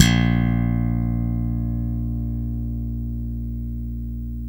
Index of /90_sSampleCDs/East Collexion - Bass S3000/Partition A/SLAP BASS-B
PENA SLAPBB1.wav